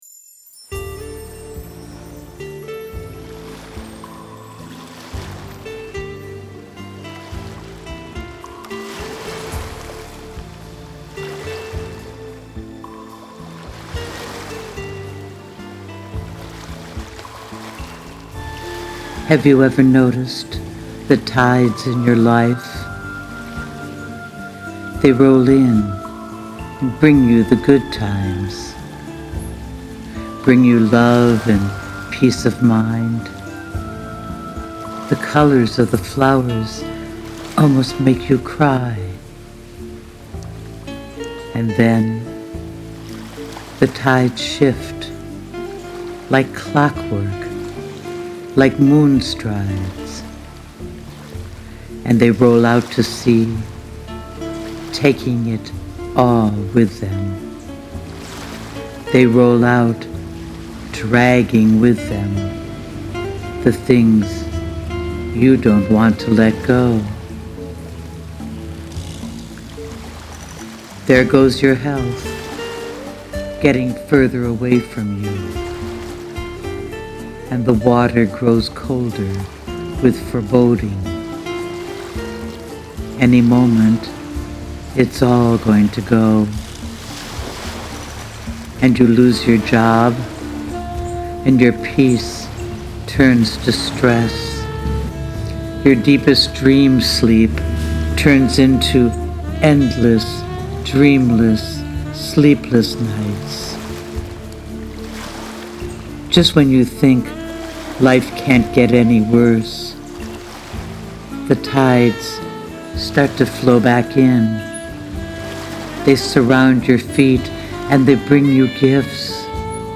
And your deep voice makes the perfect trio for reciting such a meaningful poem.